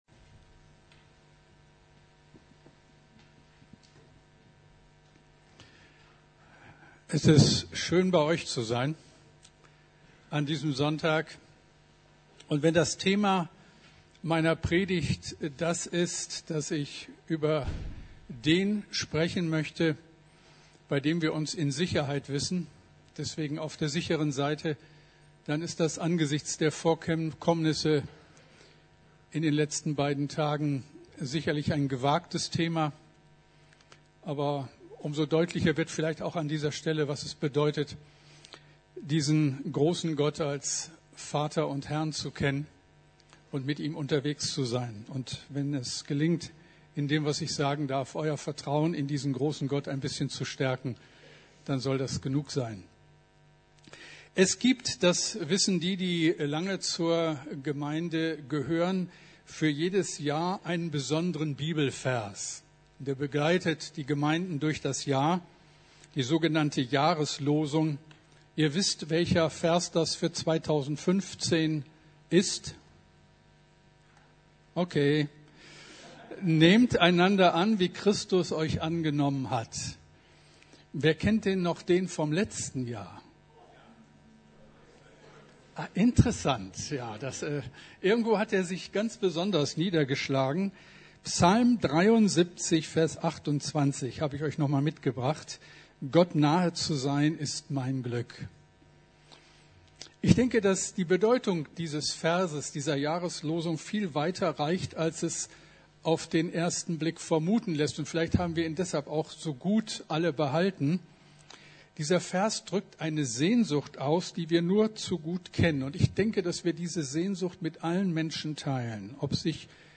Auf der sicheren Seite ~ Predigten der LUKAS GEMEINDE Podcast